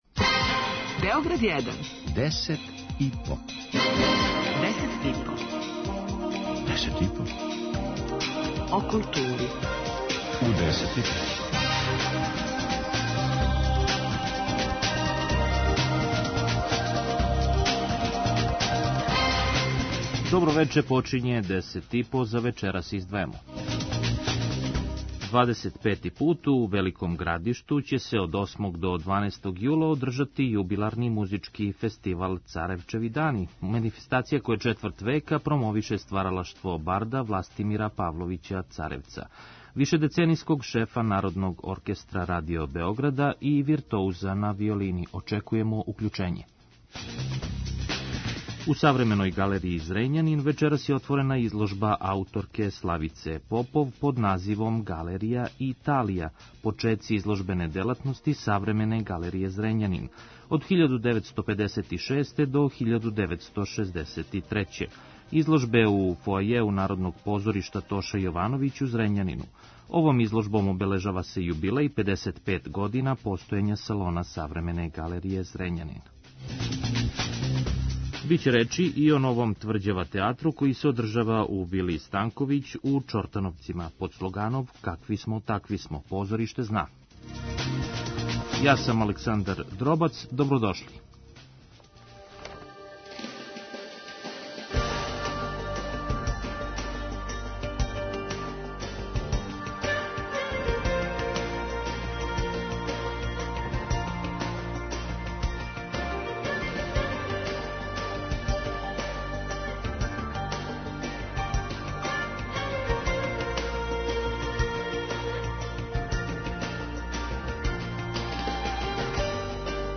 преузми : 5.35 MB Десет и по Autor: Тим аутора Дневни информативни магазин из културе и уметности.